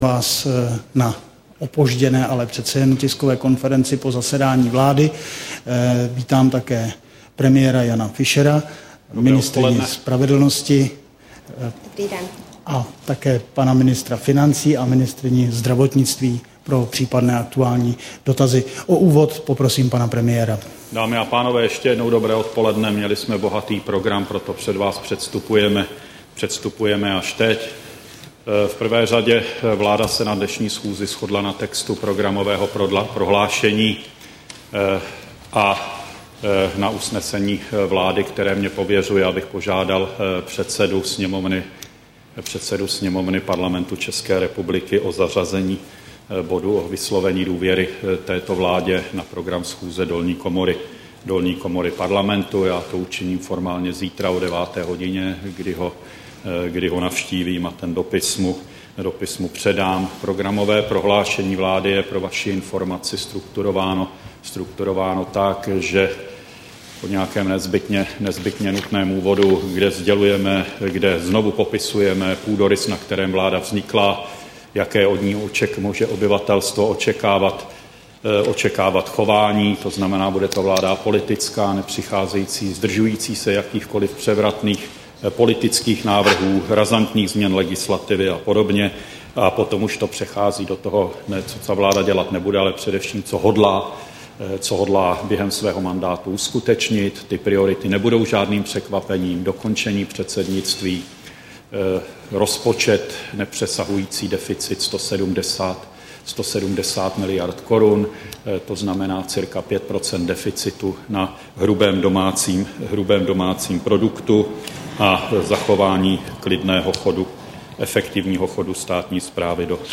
Tisková konference po zasedání vlády, 25. května 2009